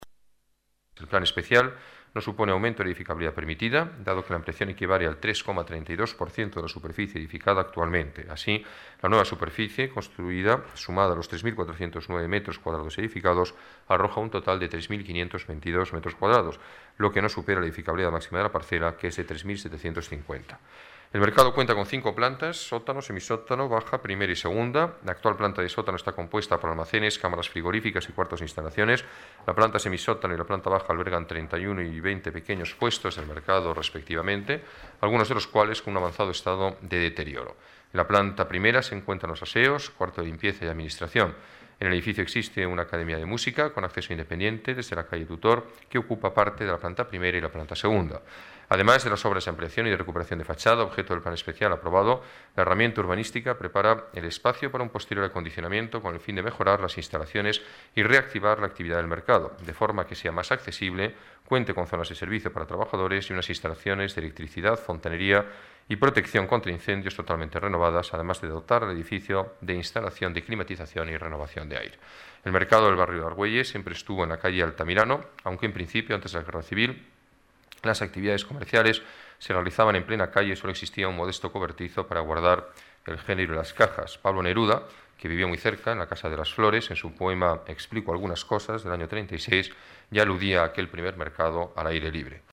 Nueva ventana:Alberto Ruiz-Gallardón, alcalde de la Ciudad de Madrid